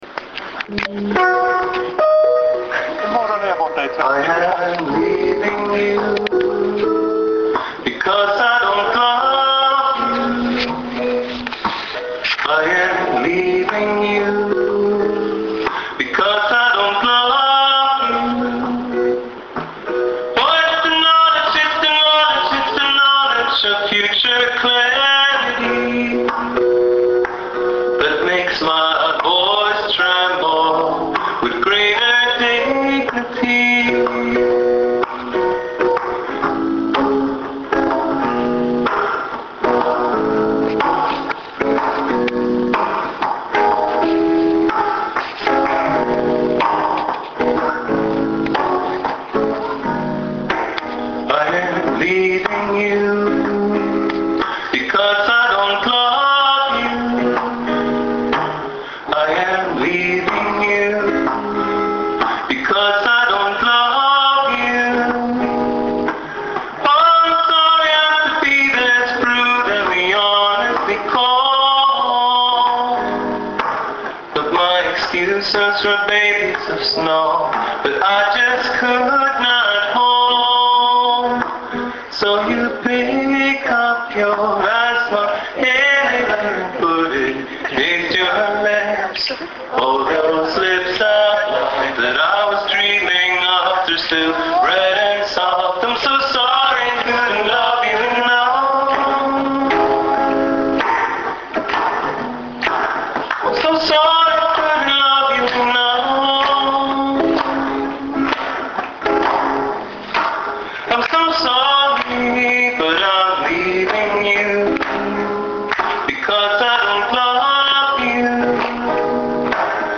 (live in Stockholm - 2007-09-28)
la canzone però sembra registrata con un grammofono :-))